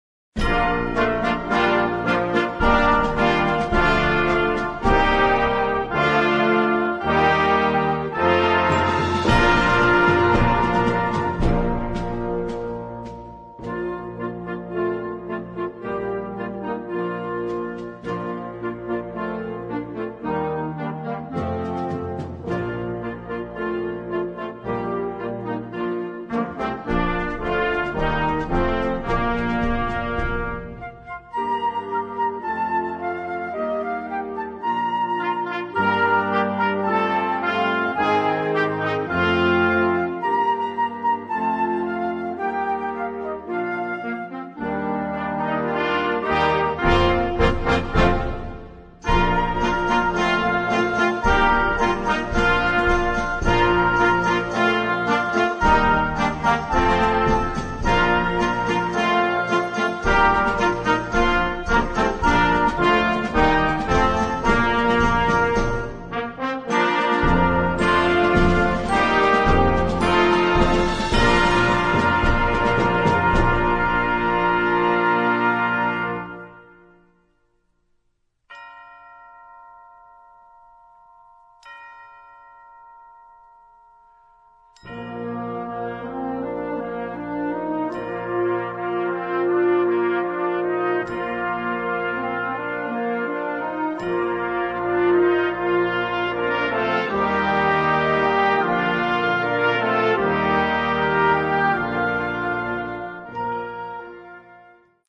Both bold and dramatic